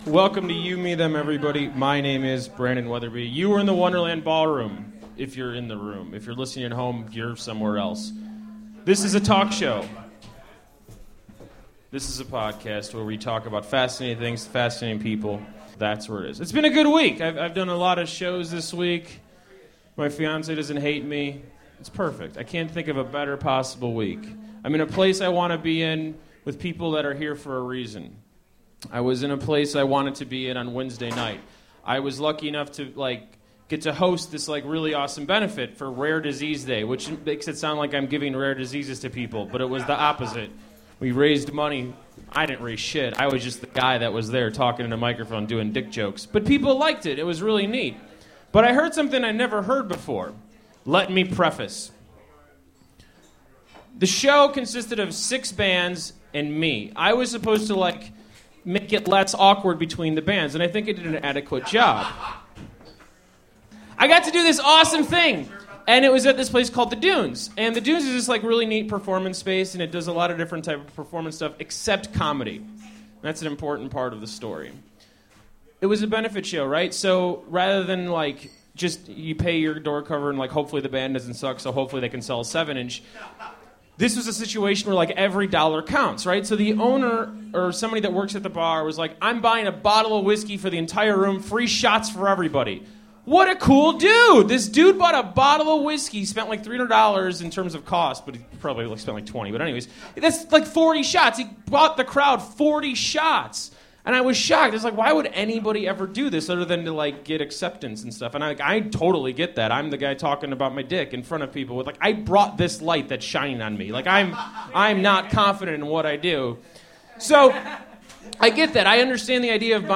Live at the Wonderland Ballroom